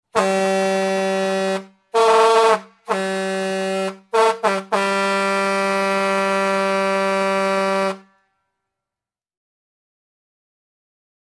Truck Semi Horn; Off Tune Blasts